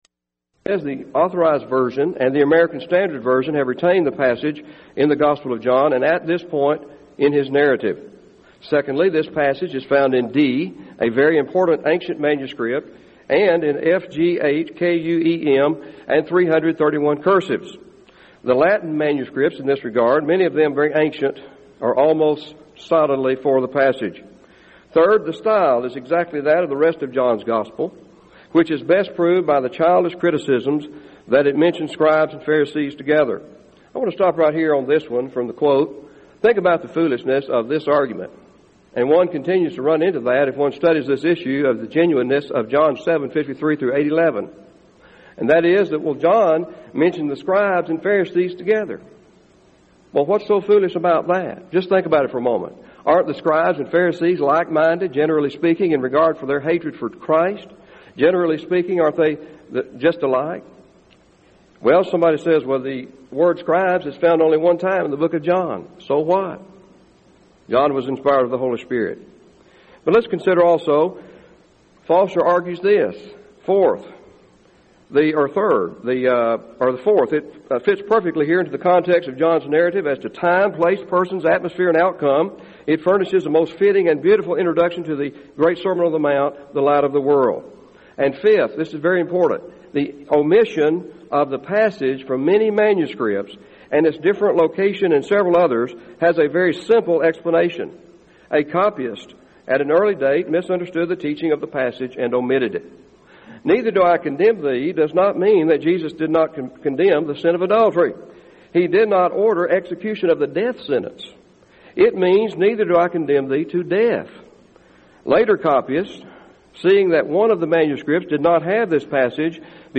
Event: 1999 Denton Lectures Theme/Title: Studies in the Book of John
lecture